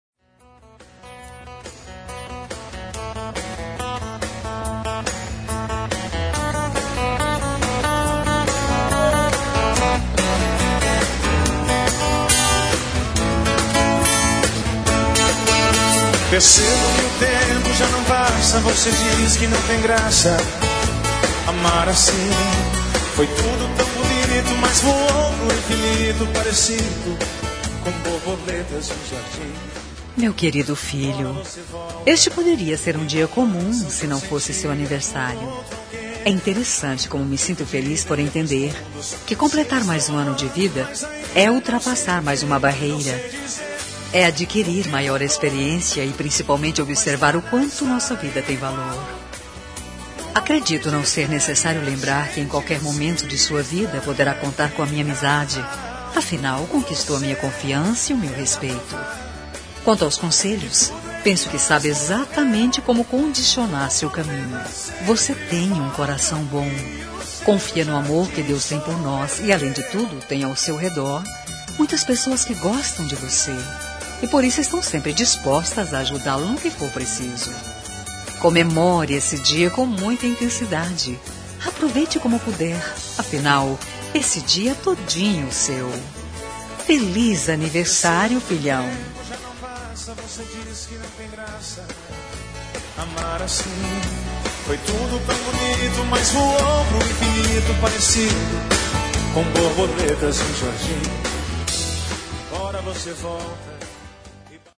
Telemensagem de Aniversário de Filho – Voz Feminina – Cód: 1844